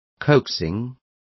Complete with pronunciation of the translation of coaxing.